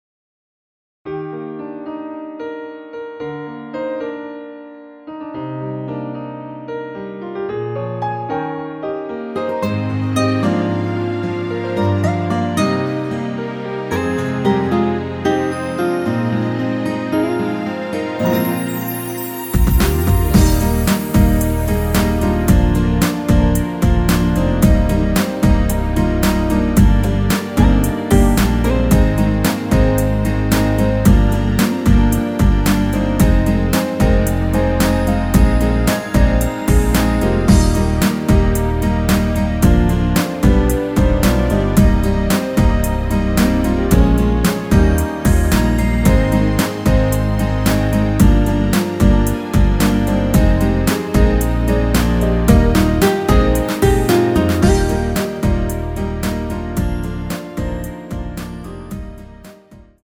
Eb
◈ 곡명 옆 (-1)은 반음 내림, (+1)은 반음 올림 입니다.
앞부분30초, 뒷부분30초씩 편집해서 올려 드리고 있습니다.
중간에 음이 끈어지고 다시 나오는 이유는